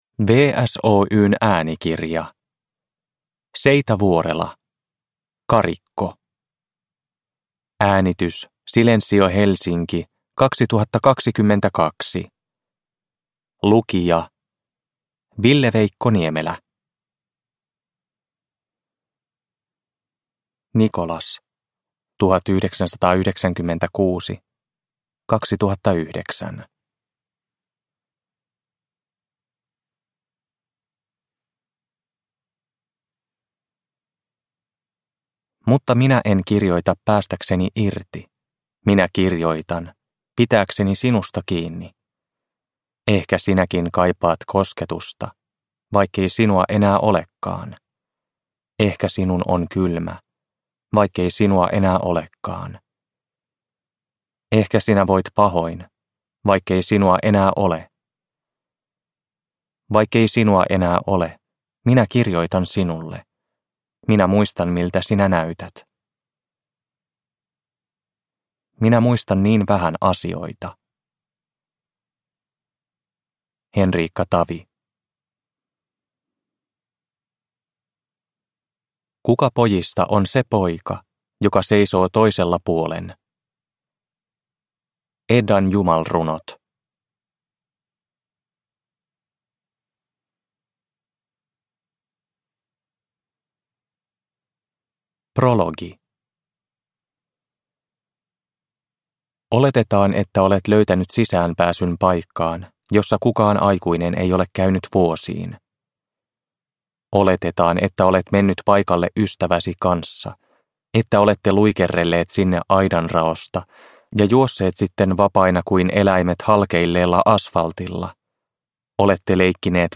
Karikko – Ljudbok – Laddas ner